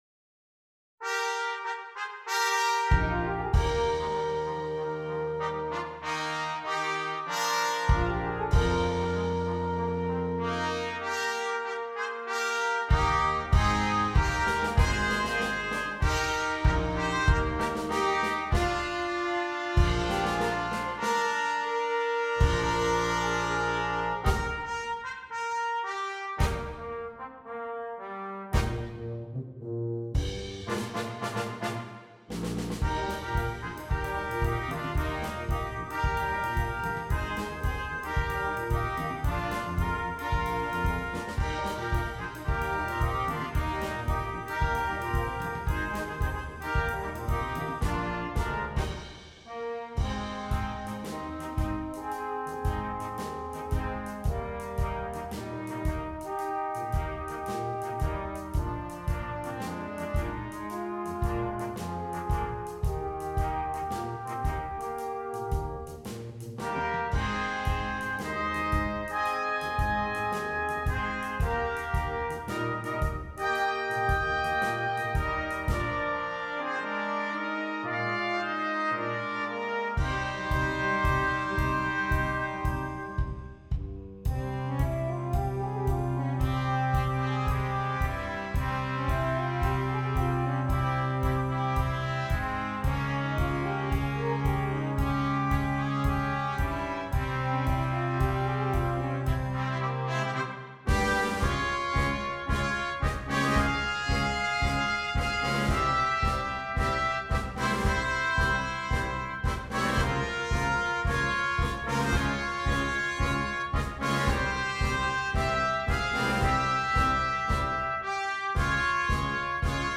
Christmas
Brass Quintet (optional Drum Set)